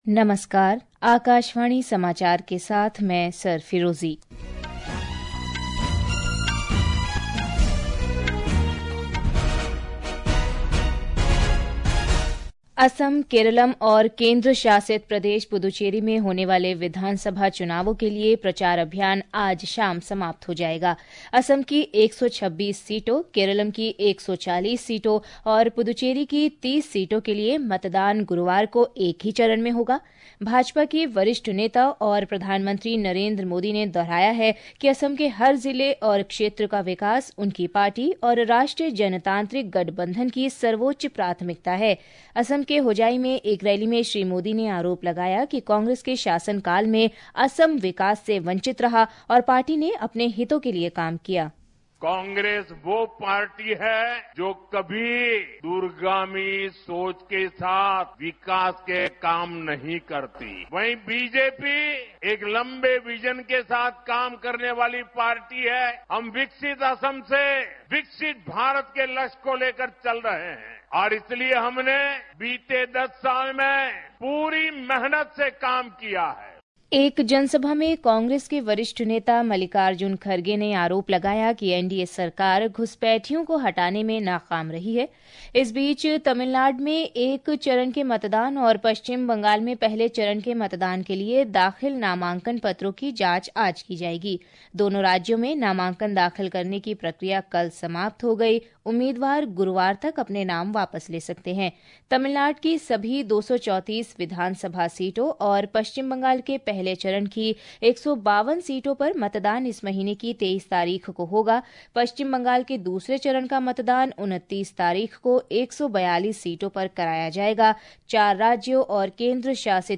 રાષ્ટ્રીય બુલેટિન
Hourly News